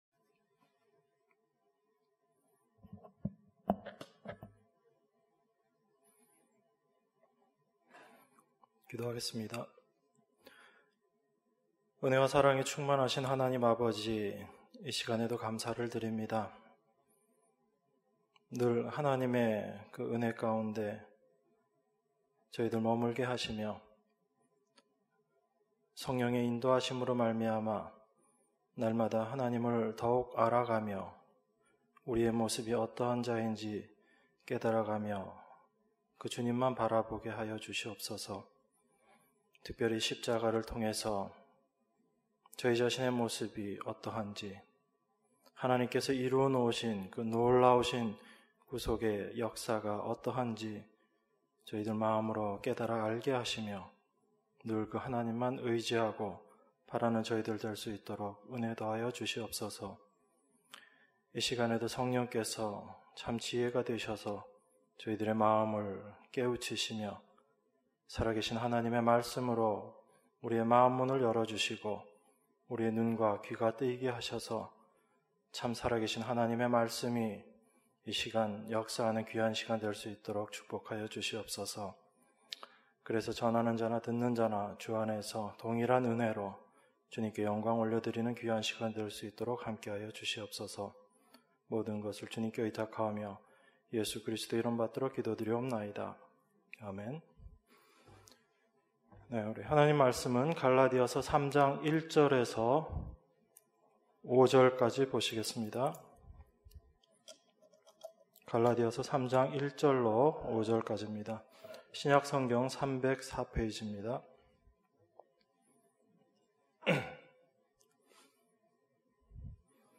수요예배 - 갈라디아서 3장 1절~5절